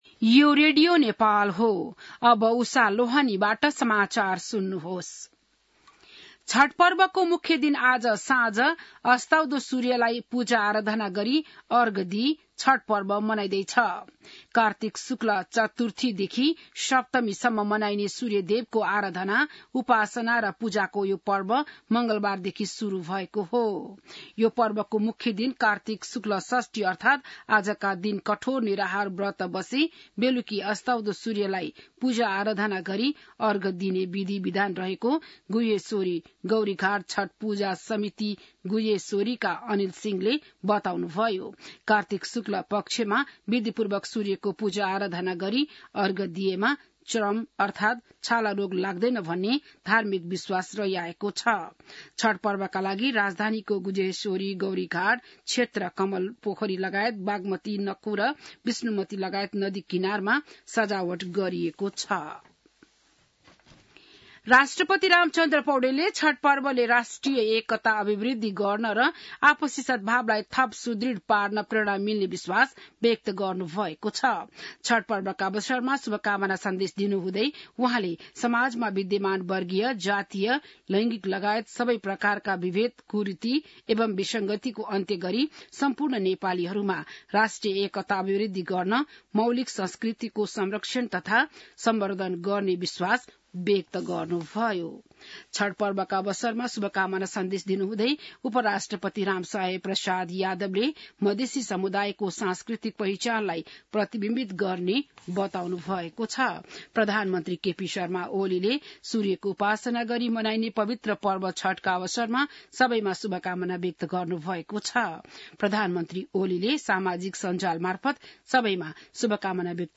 बिहान १० बजेको नेपाली समाचार : २३ कार्तिक , २०८१